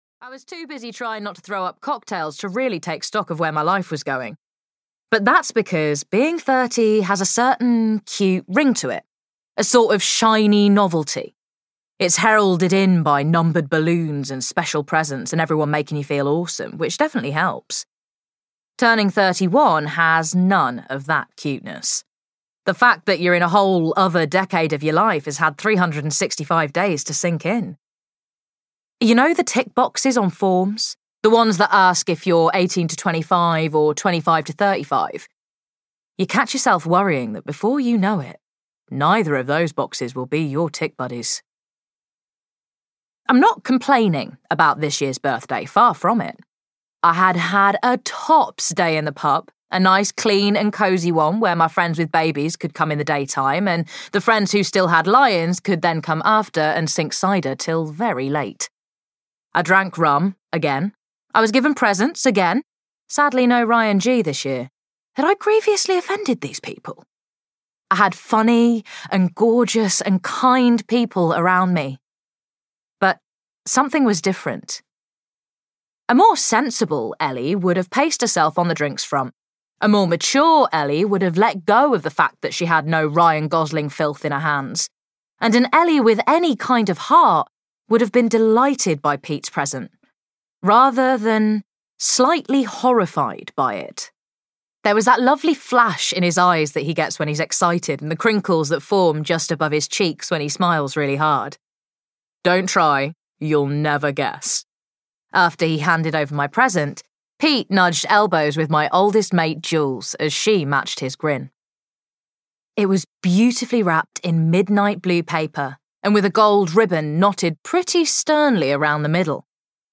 There's More To Life Than Cupcakes (EN) audiokniha
Ukázka z knihy